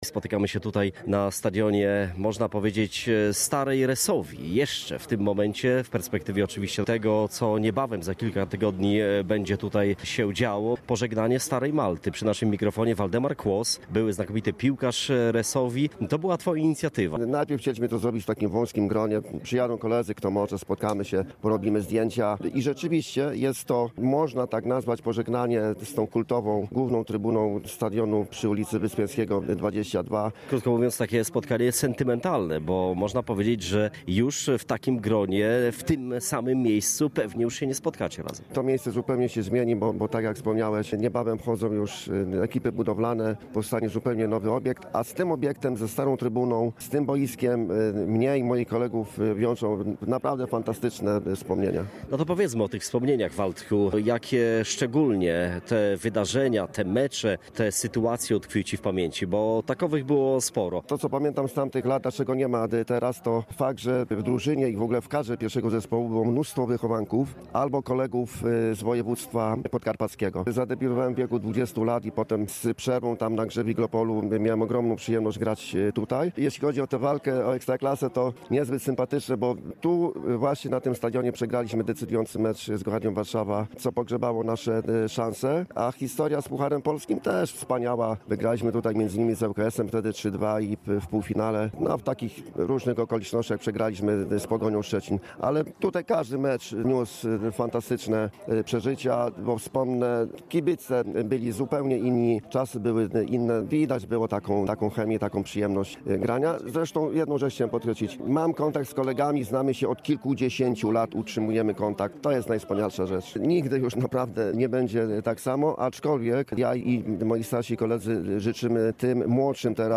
Wczoraj w samo południe na stadionie Resovii przy ulicy Wyspiańskiego spotkała się liczna grupa byłych piłkarzy, działaczy i trenerów klubu.